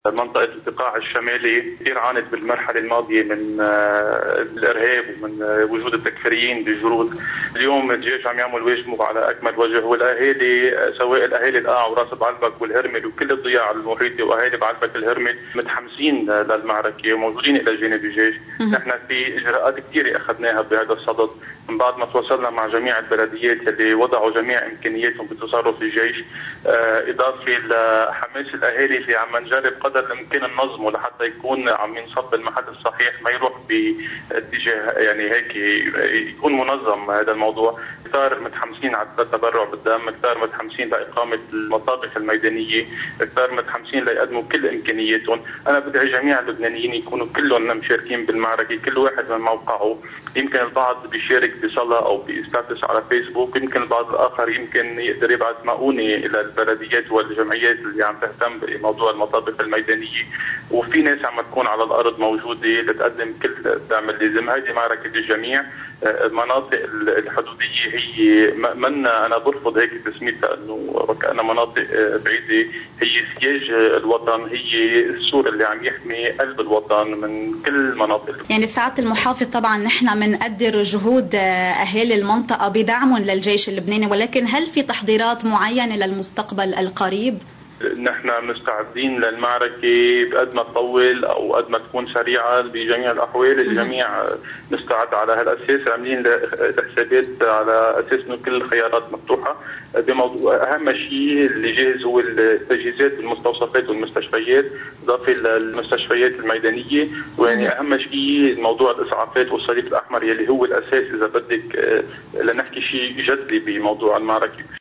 مقتطف من حديث محافظ بعلبك الهرمل بشير خضر: